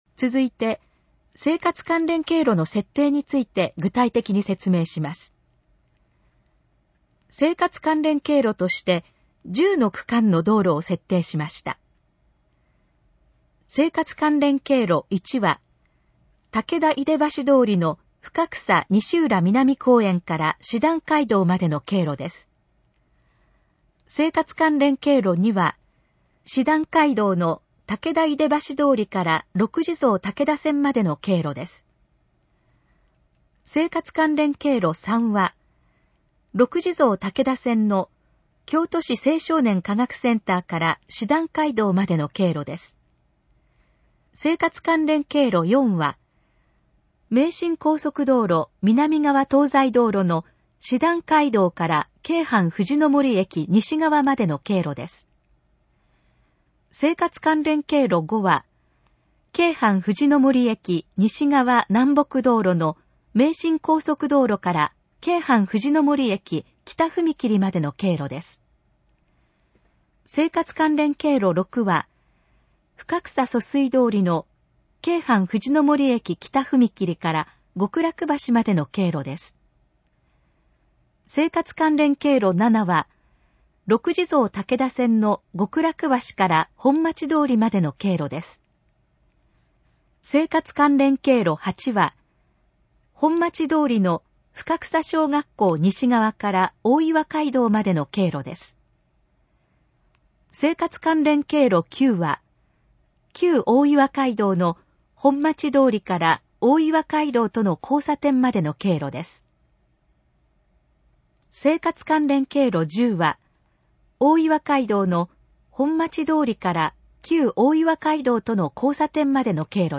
以下の項目の要約を音声で読み上げます。